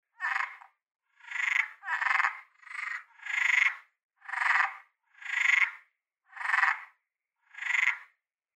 Звуки кваканья лягушки, жабы
лягушка громко квакает